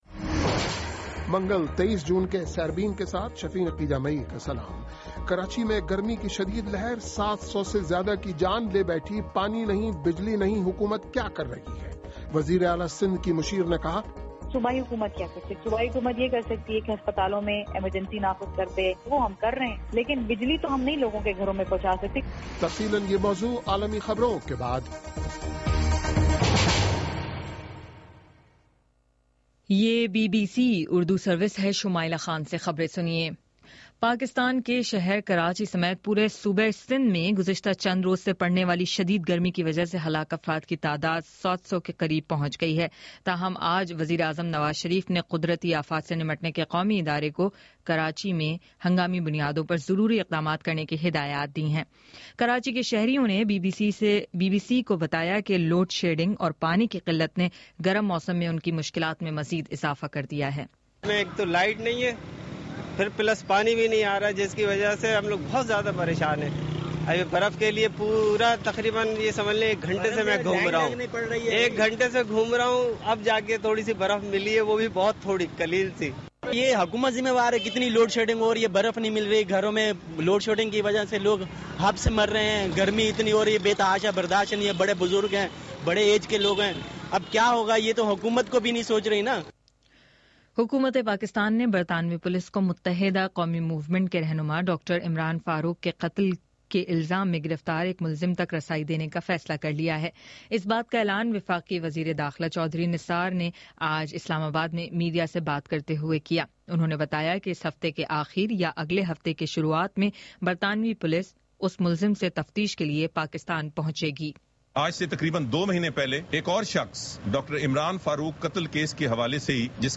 منگل 23 جون کا سیربین ریڈیو پروگرام